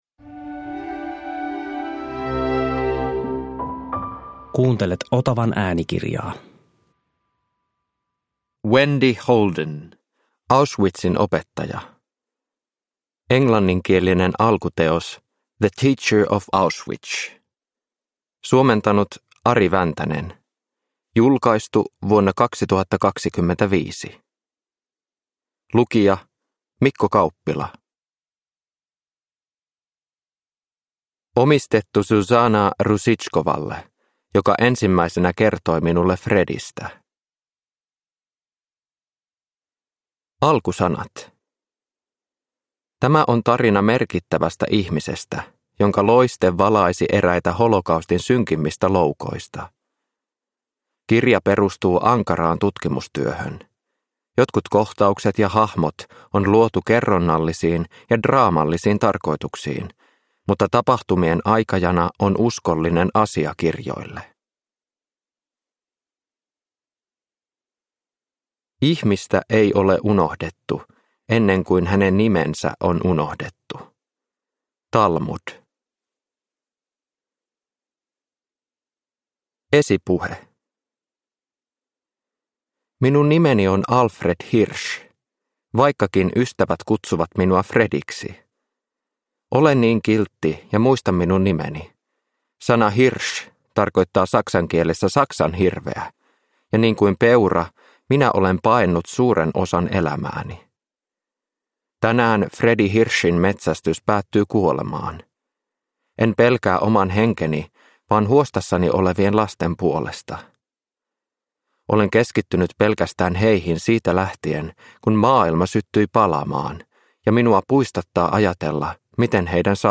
Auschwitzin opettaja – Ljudbok